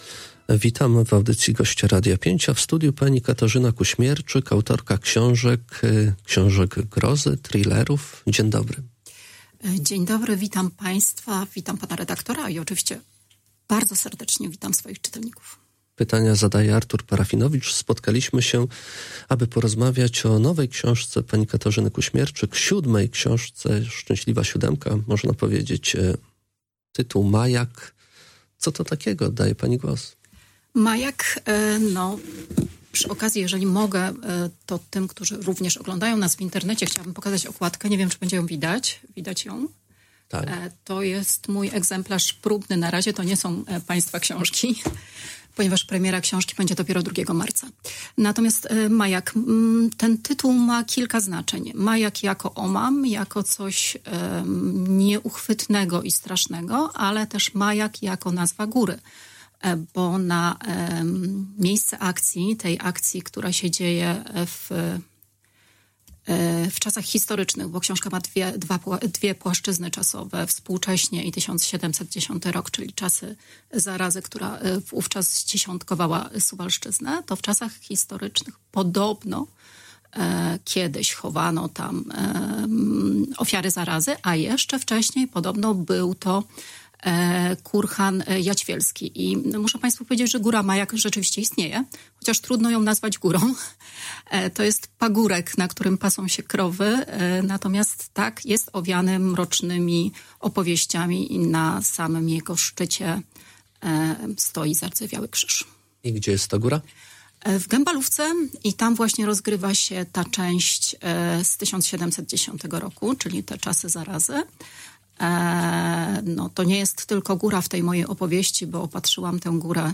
Cała rozmowa poniżej: https